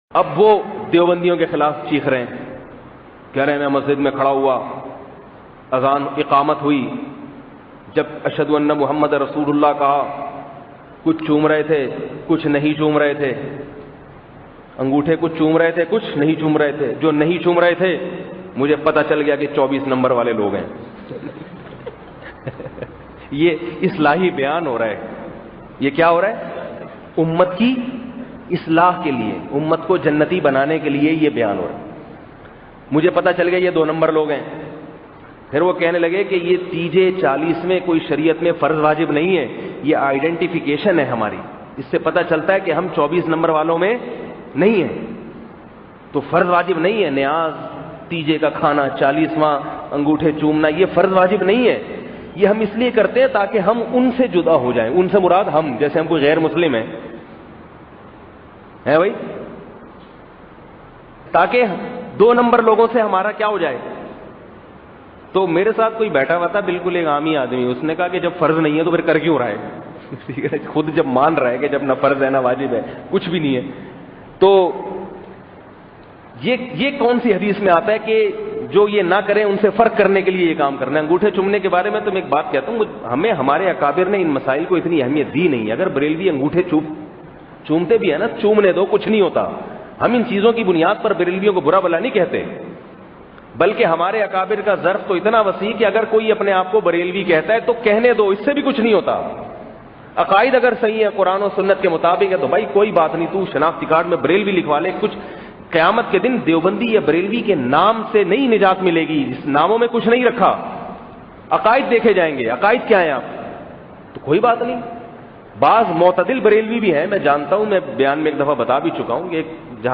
Anguthe chumna kaisa bayan mp3